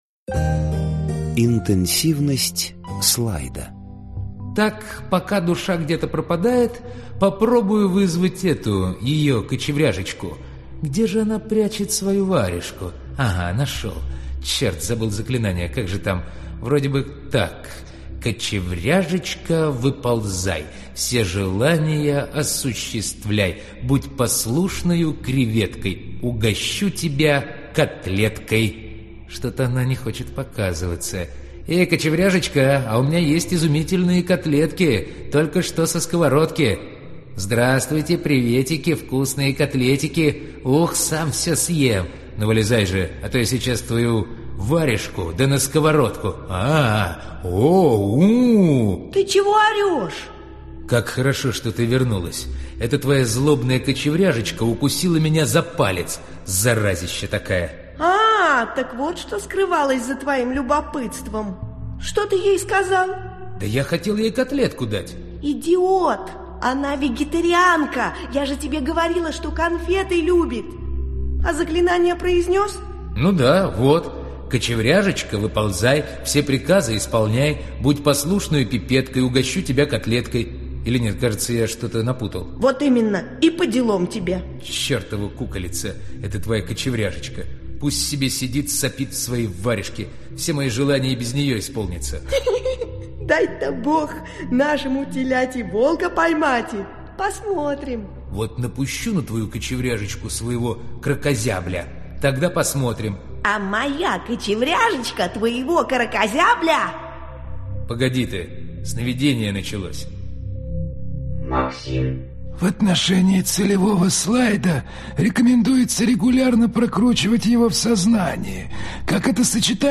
Аудиокнига Обратная связь. Часть 2 | Библиотека аудиокниг